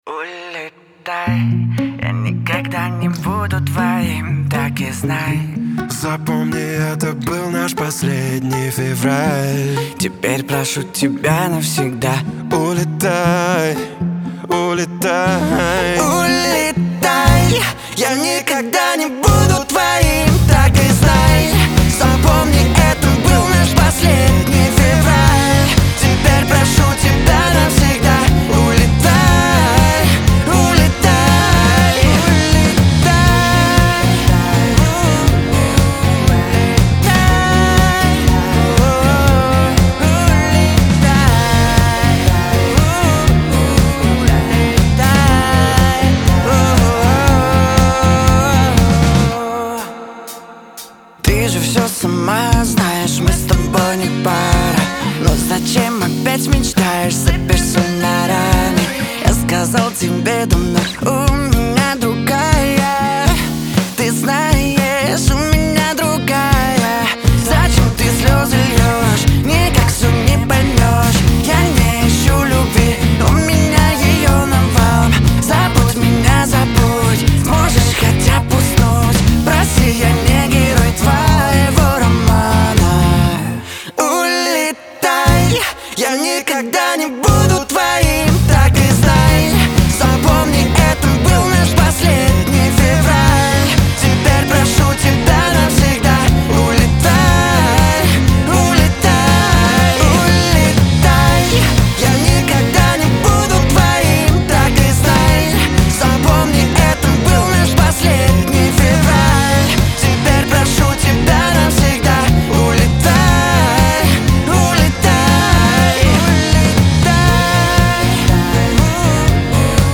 дуэт , pop
грусть